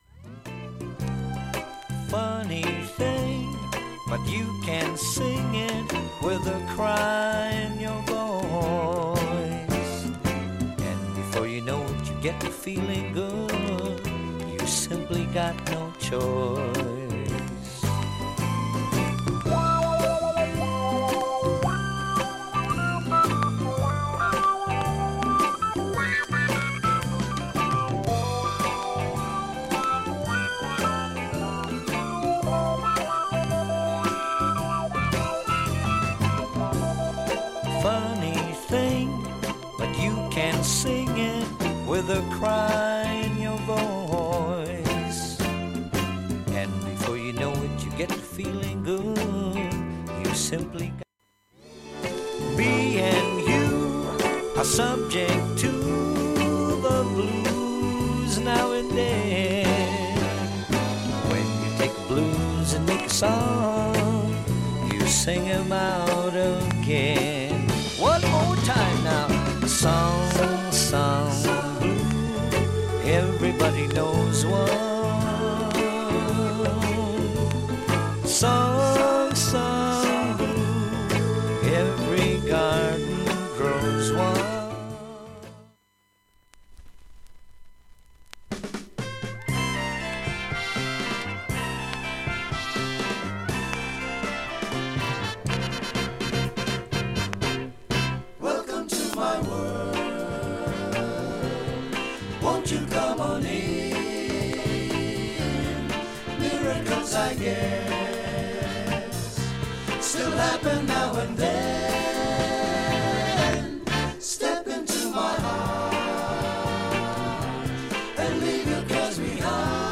プツなど出ます。
プツ、チリが出る箇所あります。
小さいプツチリあります。
ほか音質は良好全曲試聴済み。
◆ＵＳＡ盤オリジナル
活躍しているショーバンド